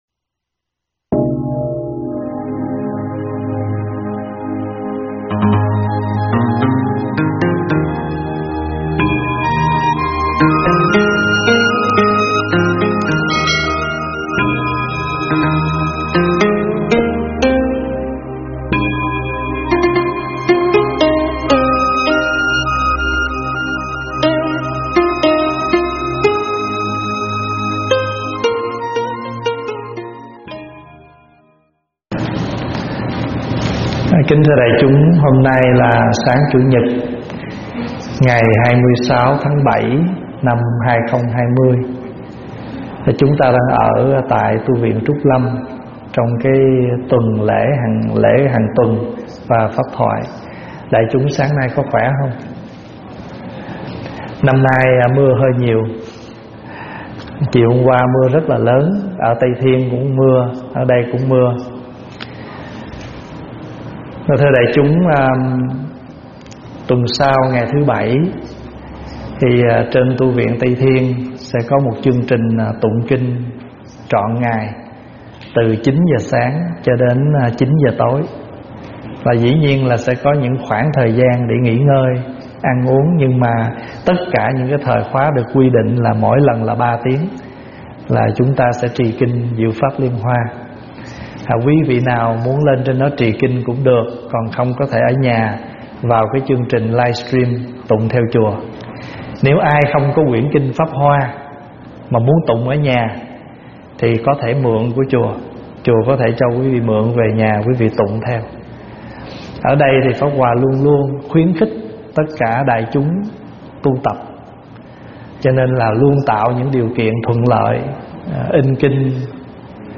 thuyết pháp
giảng tại Tv Trúc Lâm